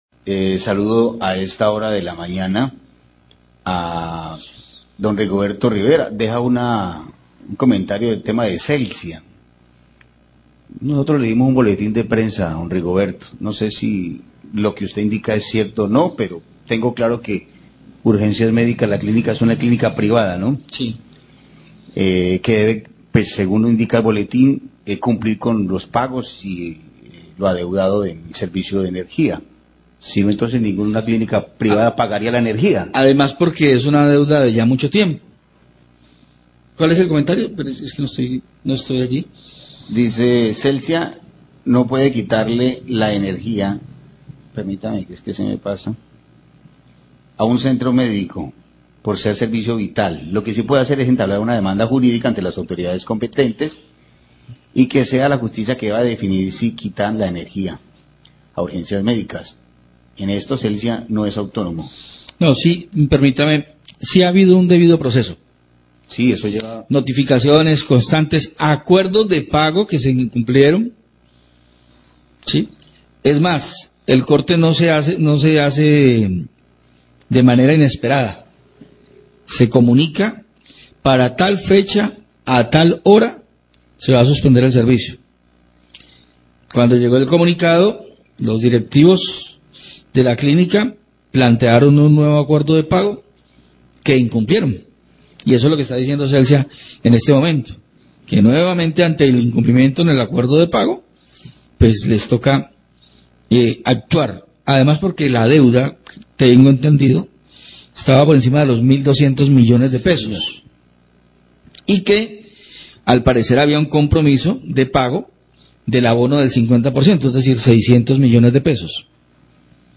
Oyente dice que Celsia no podía suspender energía a clínica y periodistas le corrigen
Radio